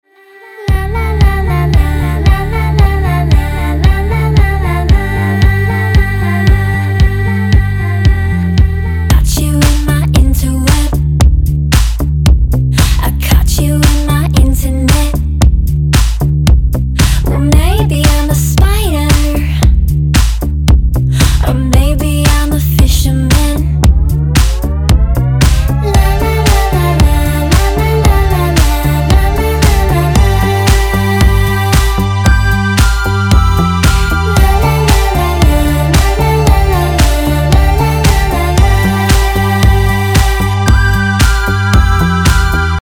• Качество: 320, Stereo
женский вокал
Electronic
techno